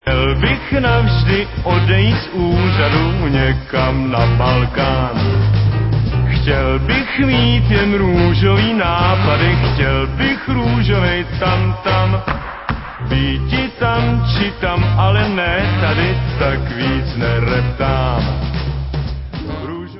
sledovat novinky v oddělení Pop/Oldies